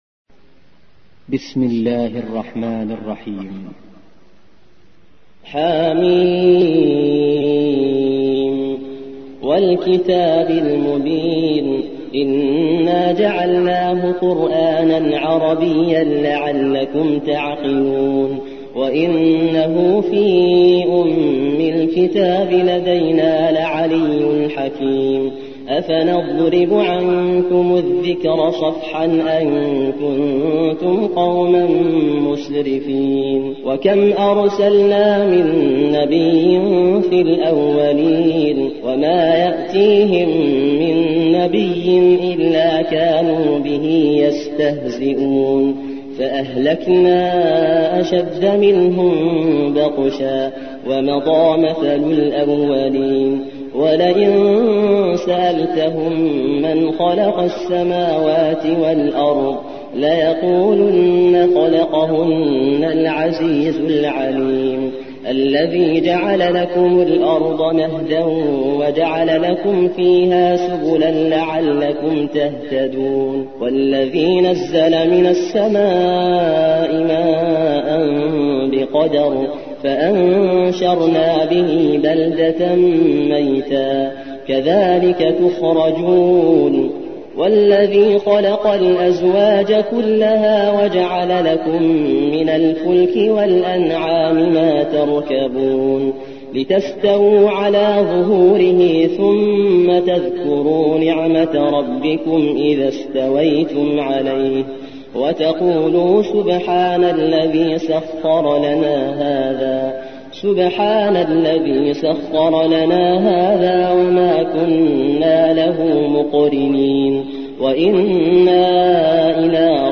43. سورة الزخرف / القارئ